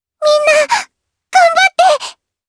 Lavril-Vox_Skill6_jp.wav